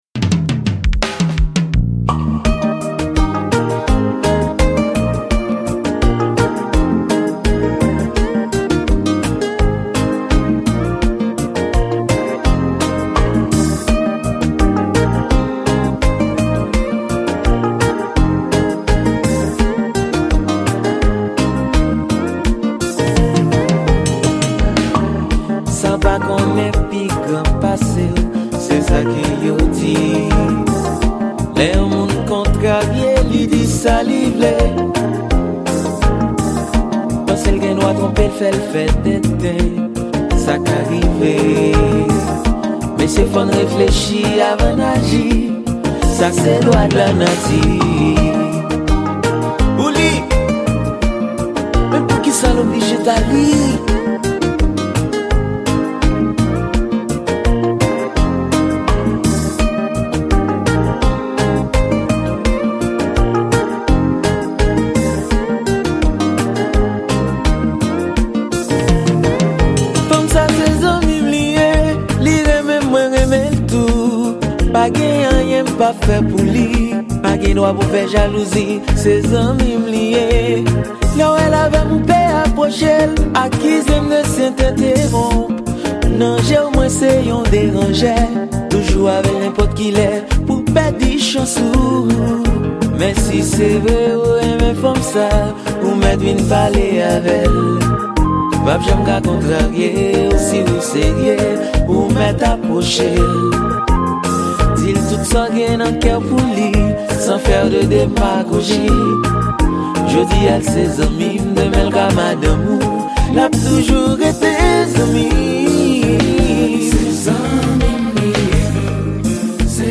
Genre: Konpa